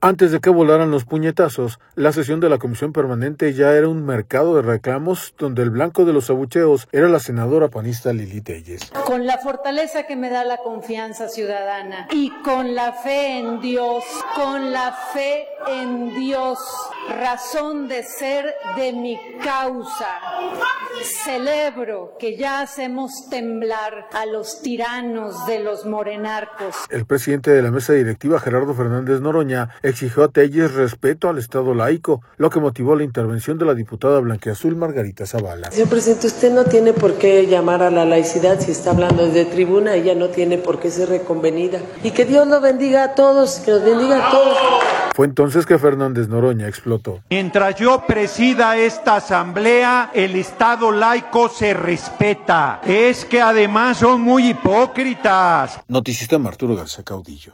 Antes de que volaran los puñetazos, la sesión de la Comisión Permanente ya era un mercado de reclamos donde el blanco de los abucheos era la senadora panista Lilly Téllez.